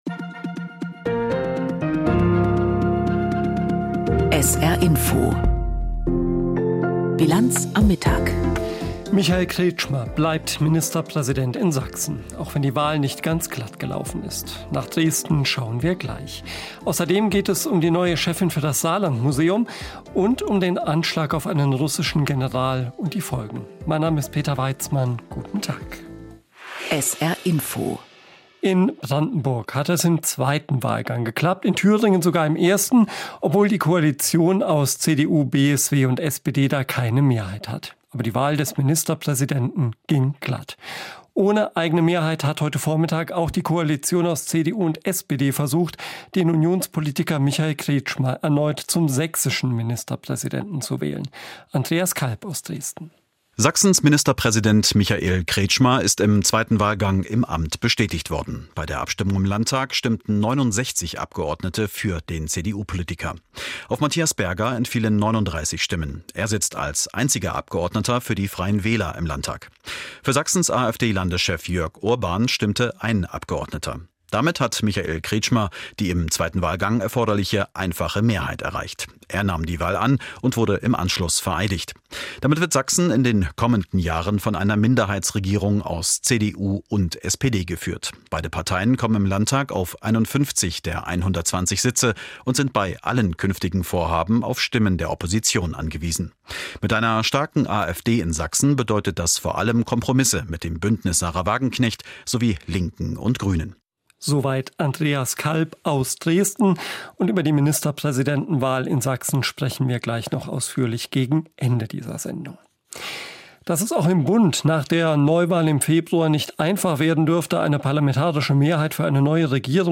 Aktuelles und Hintergrnde zu Entwicklungen und Themen des Tages aus Politik, Wirtschaft, Kultur und Gesellschaft in Berichten und Kommentaren.